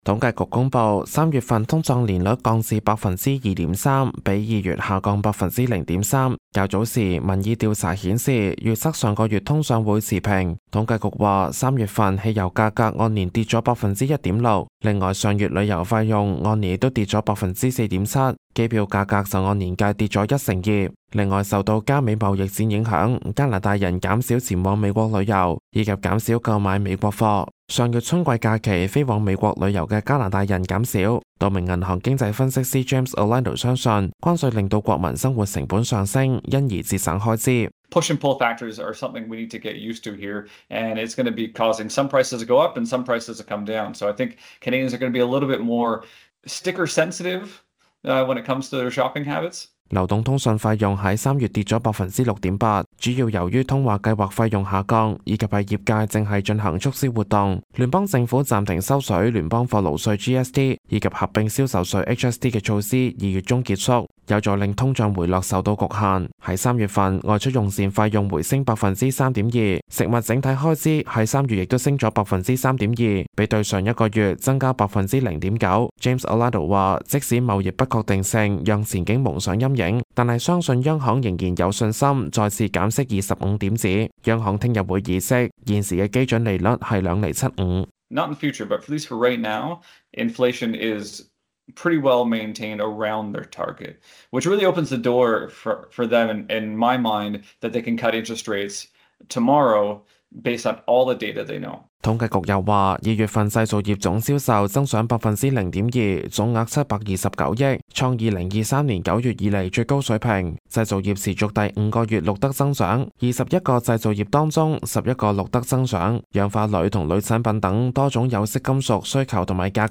news_clip_23195.mp3